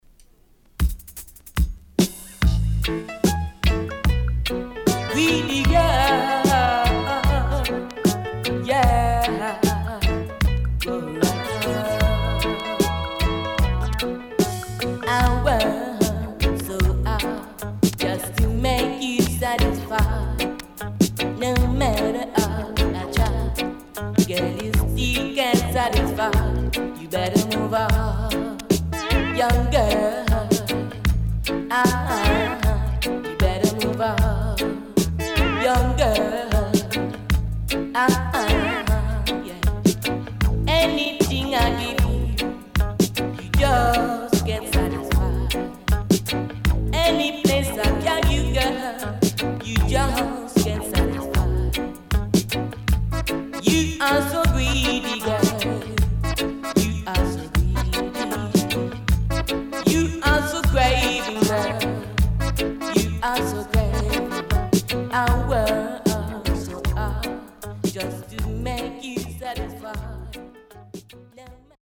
CONDITION SIDE A:VG+
Rare.Killer Tune & Sweet Dancehall
SIDE A:少しチリノイズ入ります。